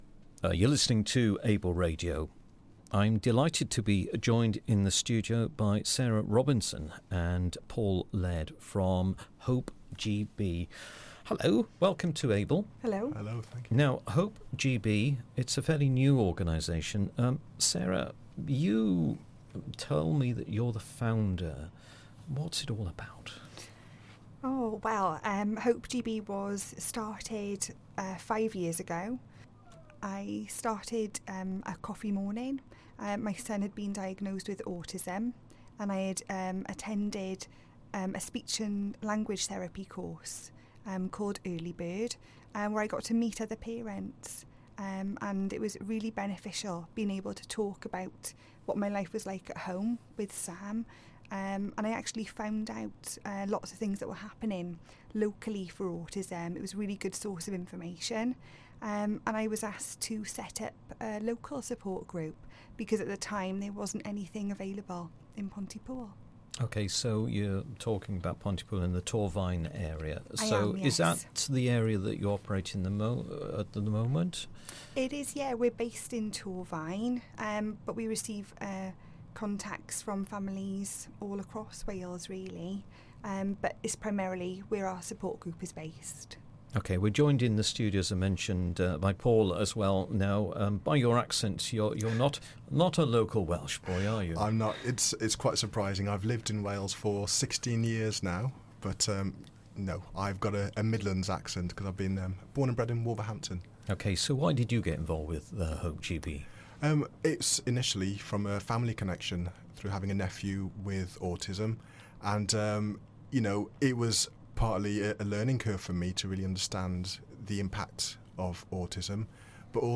Interview with Hope GB